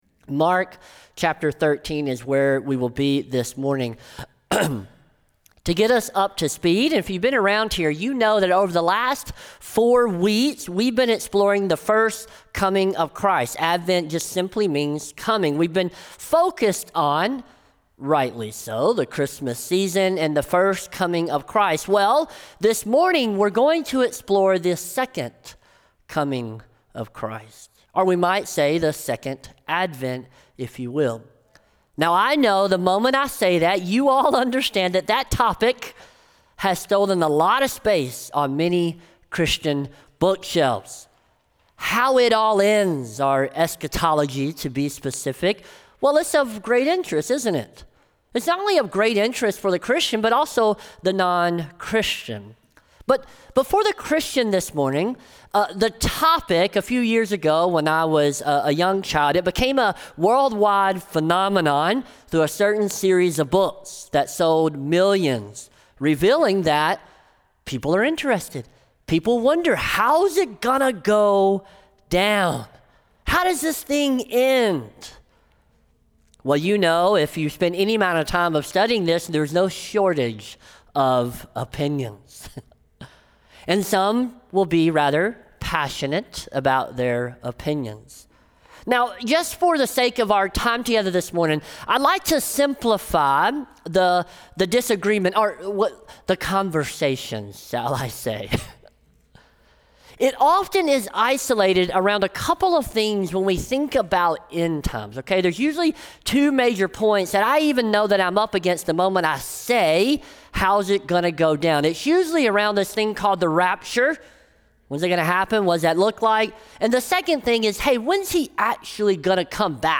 SERMON | Mark 13 | Stay Awake | Light in the Desert Church